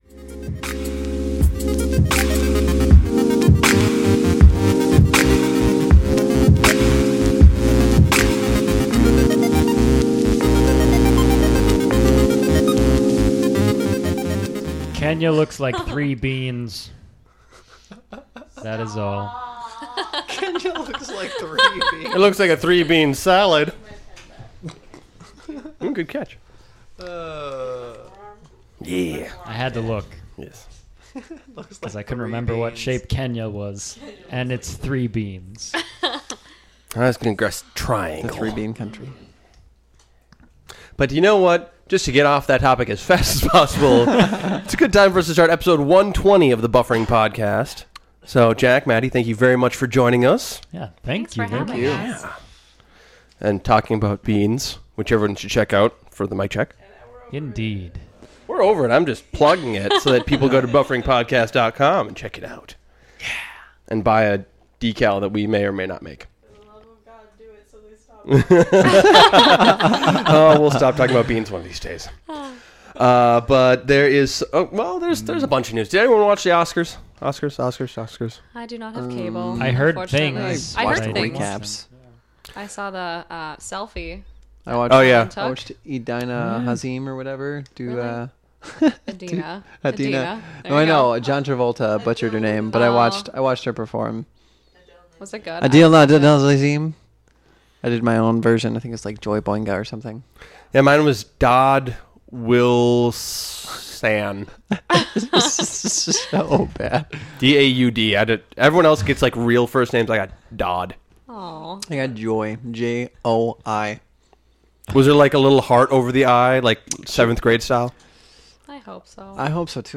Taped at The Wheels Brewing Co. Studio, Minneapolis, MN on March 4, 2014.